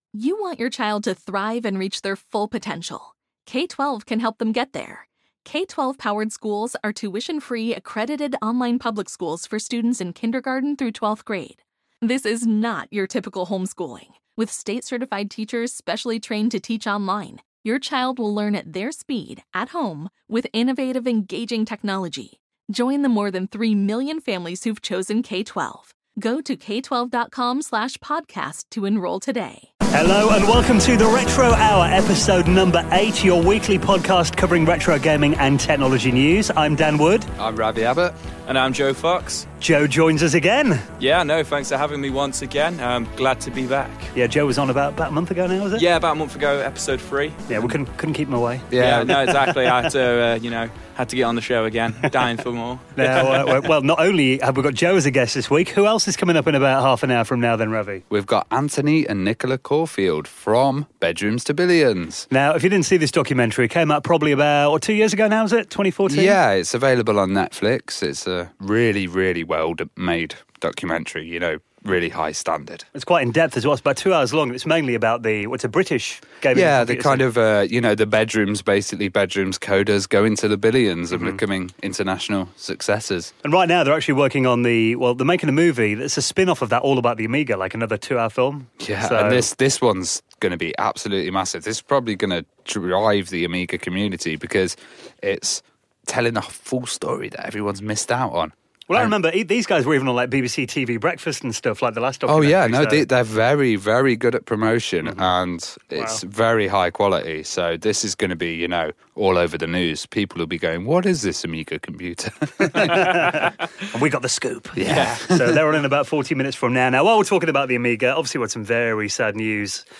The Retro Hour - Episode 8 (From Bedrooms To Billions Interview)